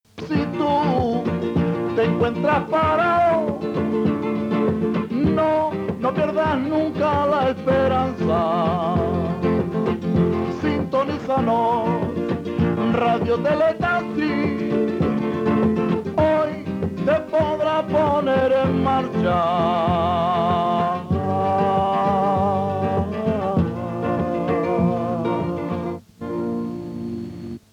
Cançó indicatiu de l'emissora.
FM